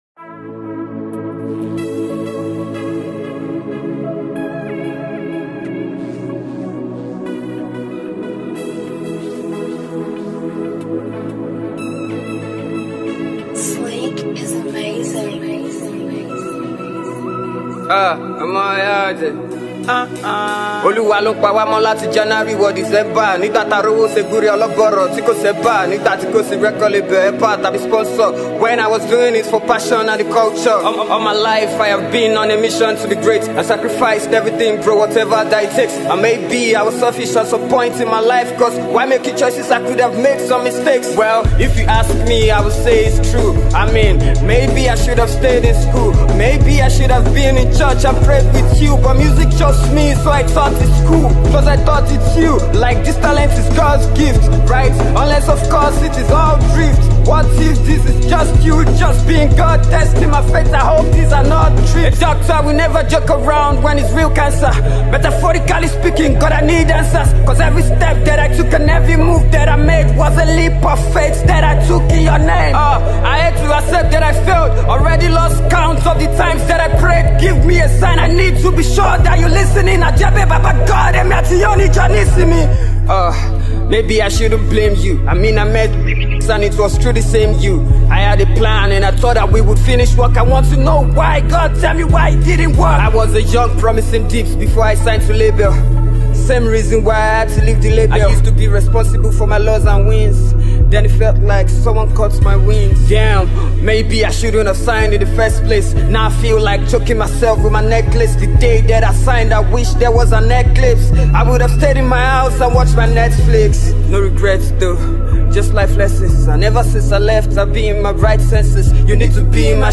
Yoruba rap